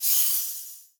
Simple Digital Connection 1.wav